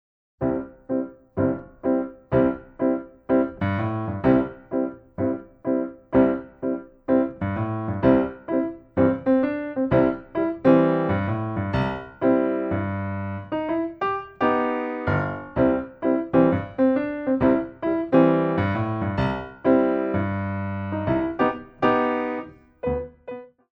Tendu